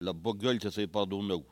Langue Maraîchin
Patois - archive
Catégorie Locution